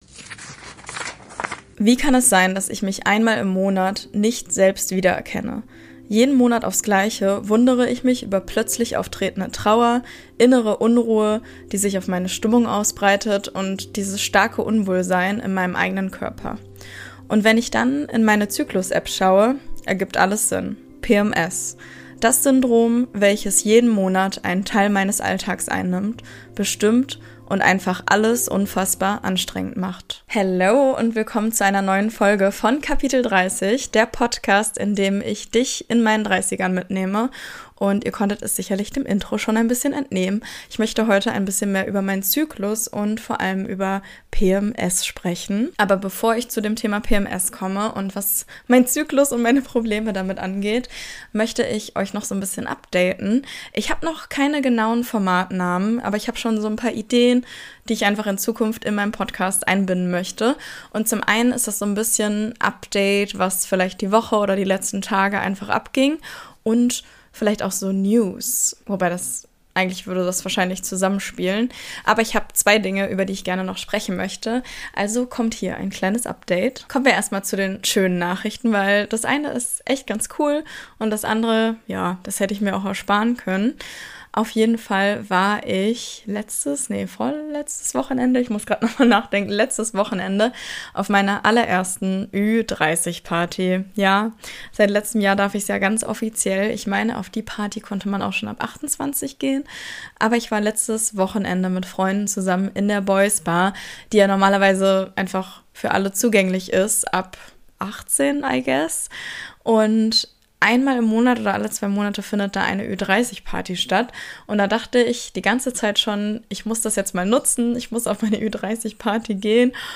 Diese Folge ist roh, ehrlich und ein bisschen wütend – aber auch befreiend.